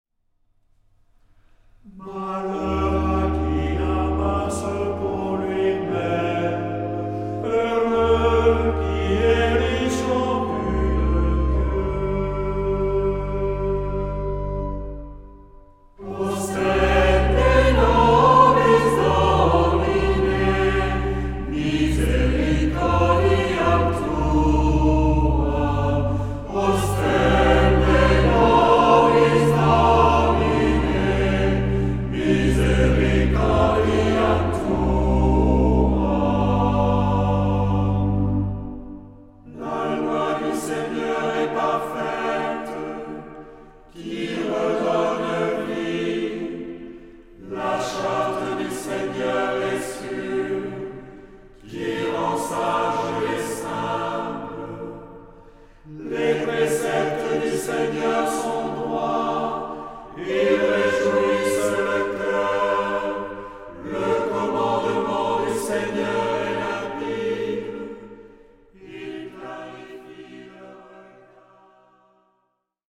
Genre-Style-Form: troparium ; Psalmody
Mood of the piece: collected
Type of Choir: SATB  (4 mixed voices )
Instruments: Organ (1) ; Melody instrument (optional)
Tonality: C minor